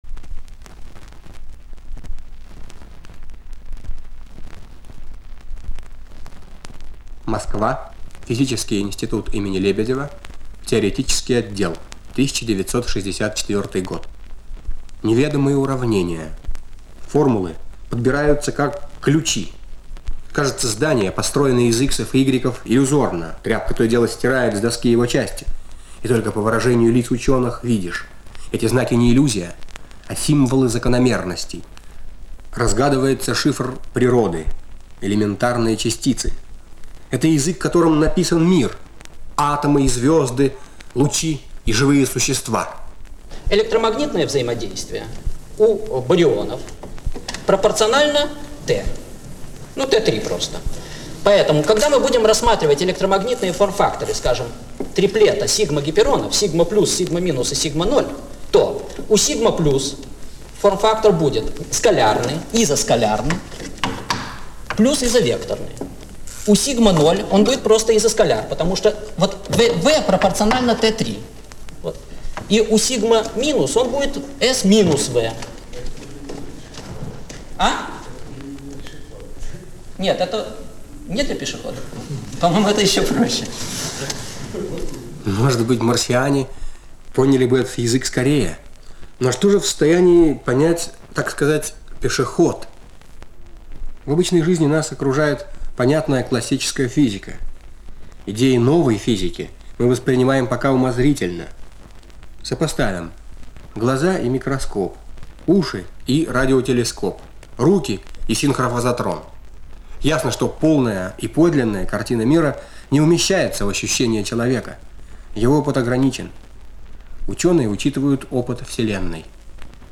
На звуковой странице - отрывок из почти домашней дискуссии современных физиков.
Звуковая страница 2 - Репортаж из неведомого уравнения. Семинар ведёт академик И.Е.Тамм.